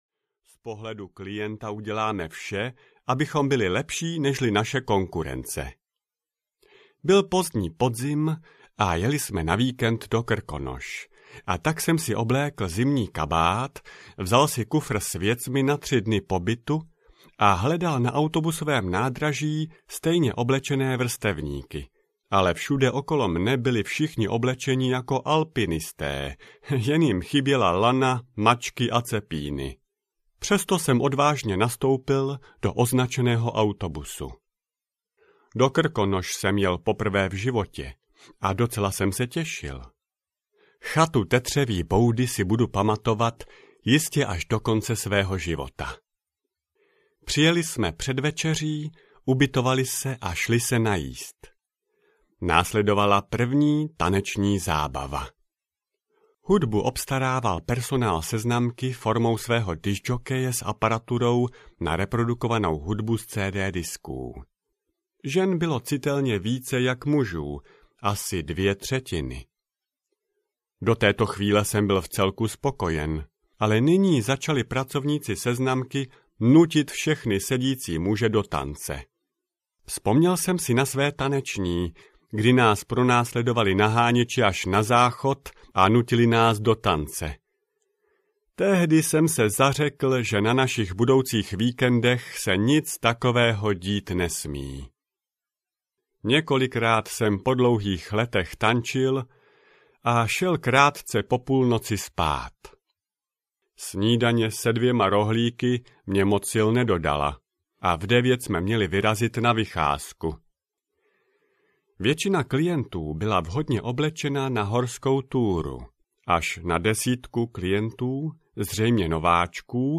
Audio knihaPovídky ze seznamovacích víkendů a dovolených
Ukázka z knihy